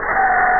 camera.mp3